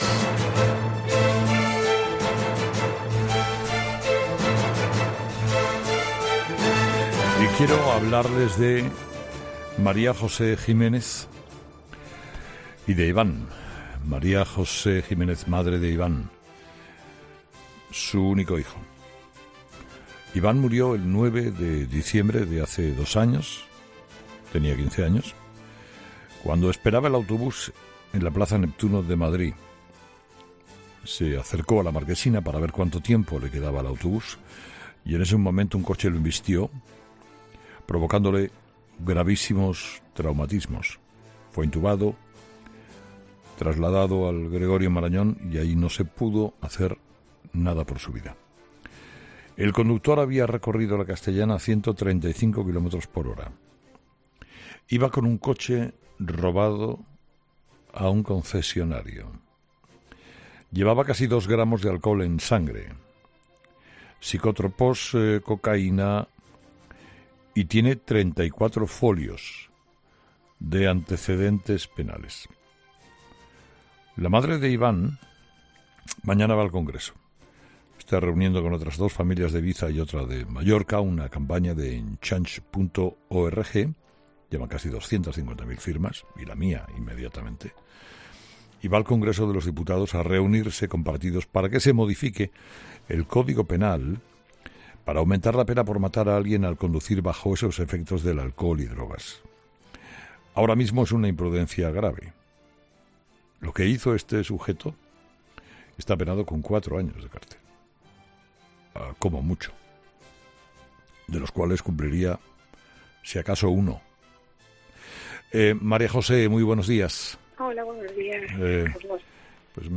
En una entrevista en la víspera en 'Herrera en COPE'